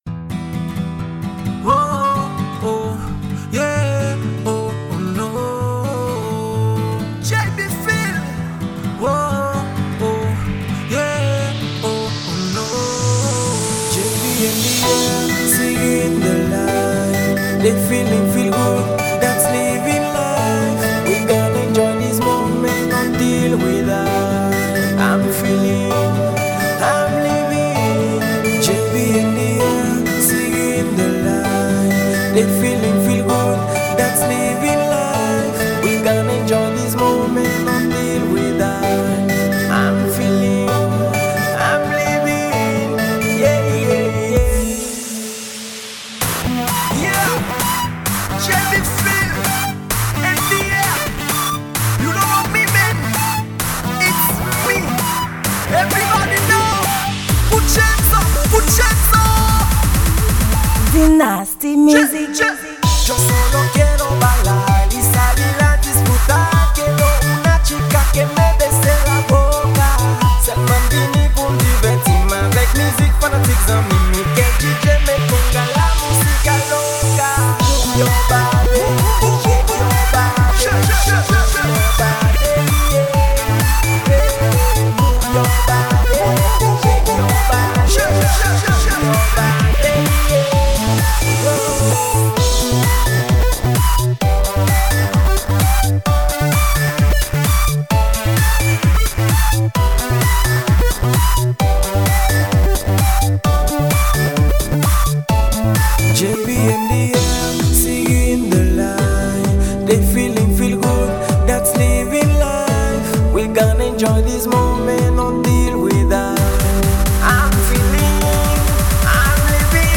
Genre: Techno.